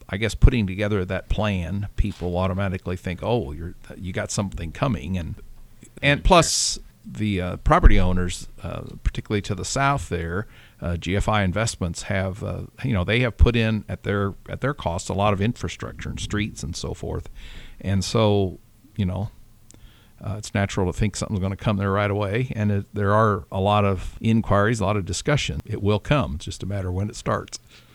Washington Mayor Joe Wellman appeared on WAMW over the weekend and talked about many issues and topics.